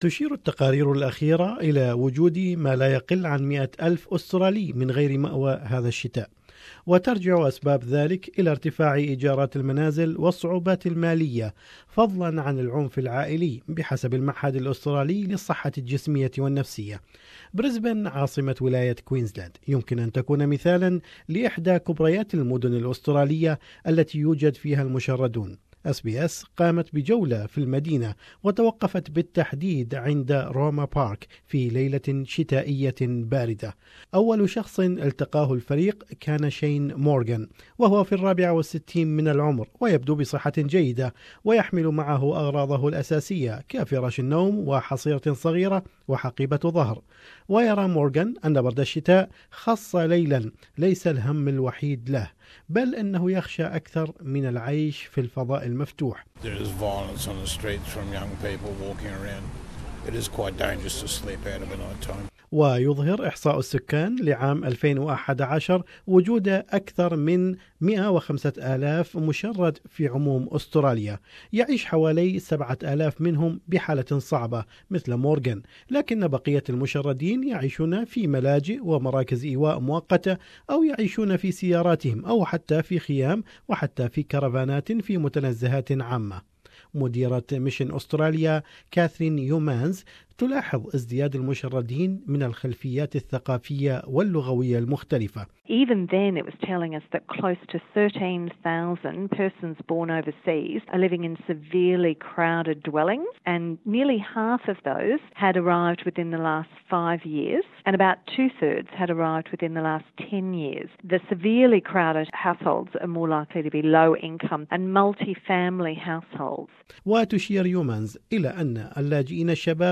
Housing, financial difficulties and domestic violence are the top three causes of homelessness, according to the Australian Institute of Health and Welfare. SBS takes you to an inner-city park in Brisbane to explore what it means to be homeless during Homelessness Awareness Week.